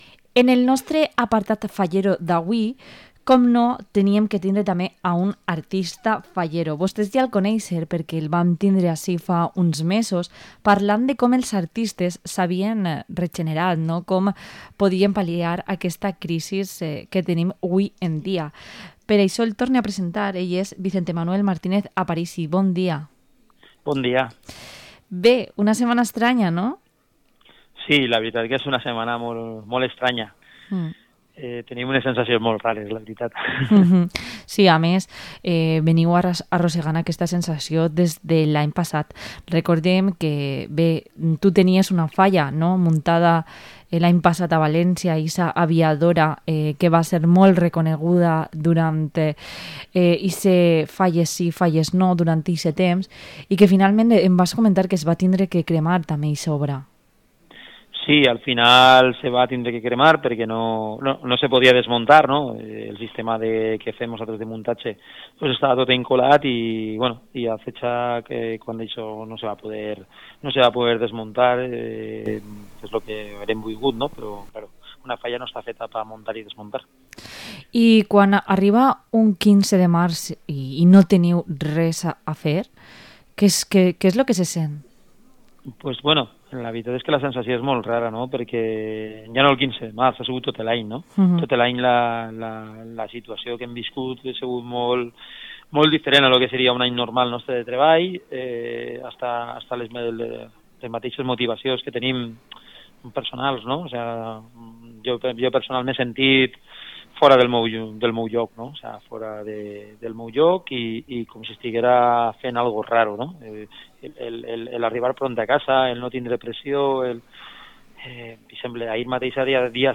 Entrevista al artista fallero